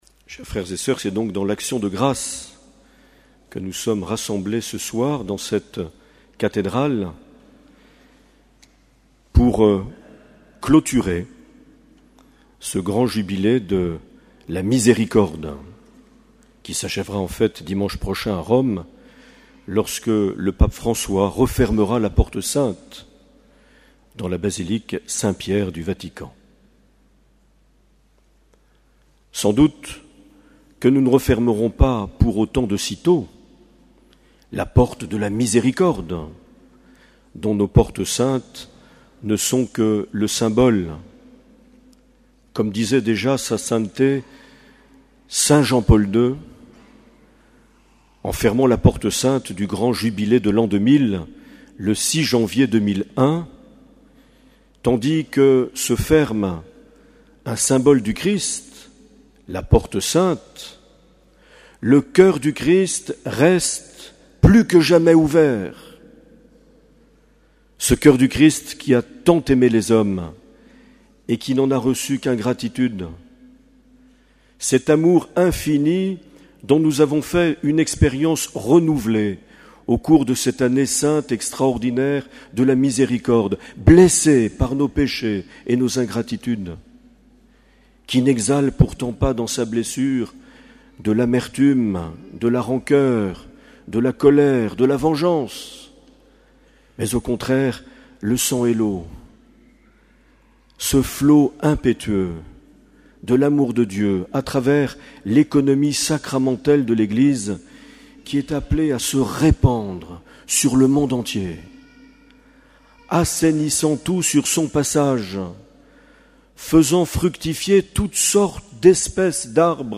13 novembre 2016 - Cathédrale de Bayonne - Clôture du jubilé de la miséricorde
Accueil \ Emissions \ Vie de l’Eglise \ Evêque \ Les Homélies \ 13 novembre 2016 - Cathédrale de Bayonne - Clôture du jubilé de la (...)
Une émission présentée par Monseigneur Marc Aillet